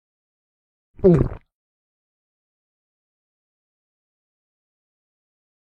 Gulp Sound Effect
Gulp-Sound-Effect.mp3